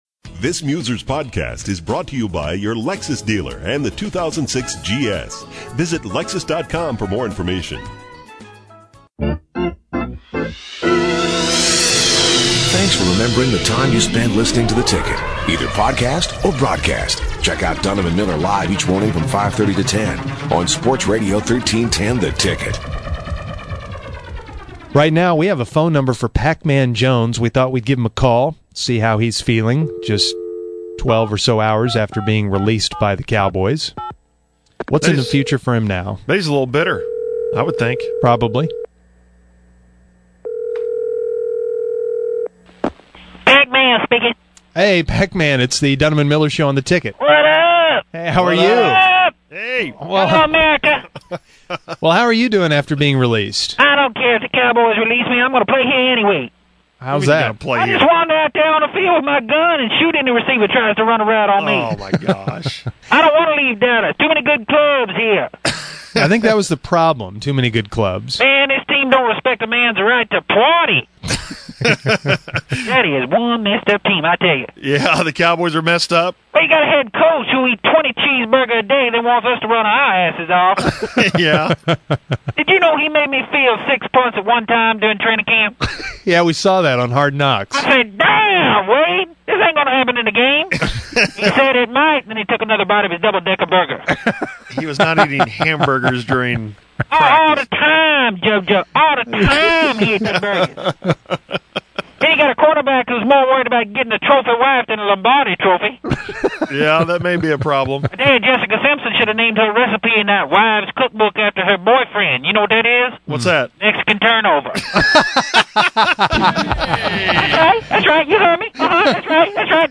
Fake Pacman Speaks After Being Cut